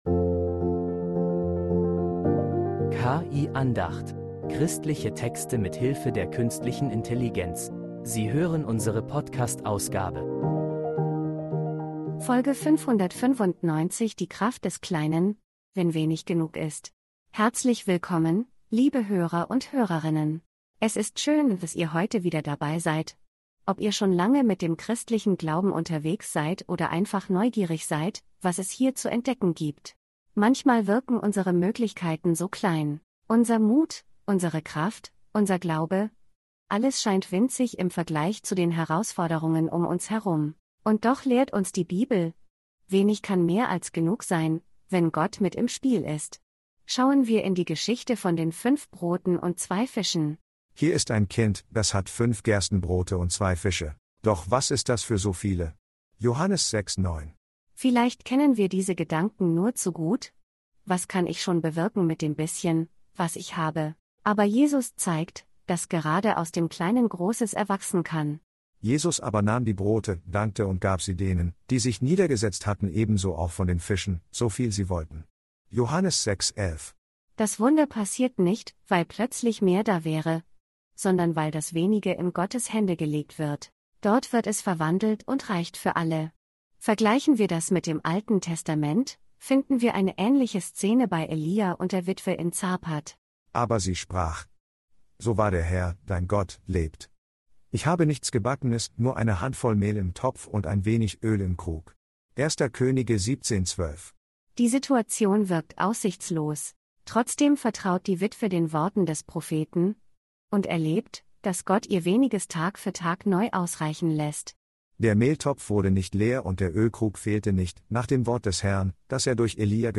Christliche Texte mit Hilfe der Künstlichen Intelligenz